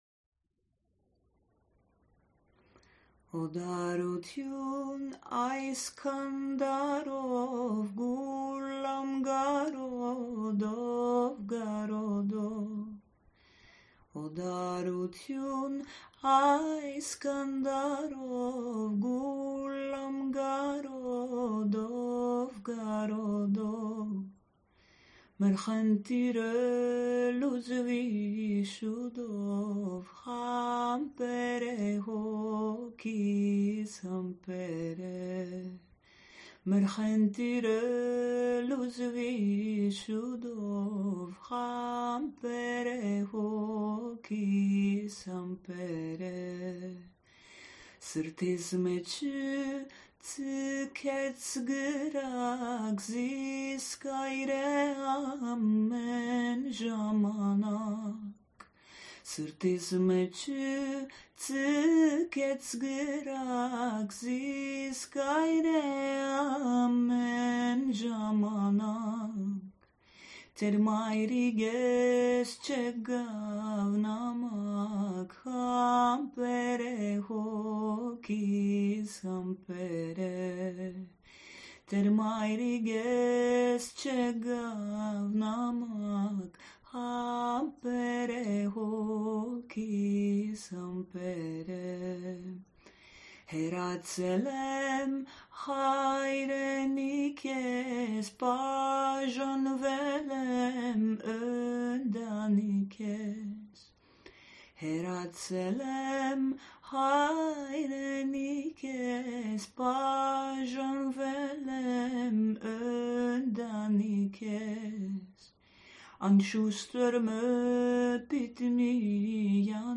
Armenian folk song
voice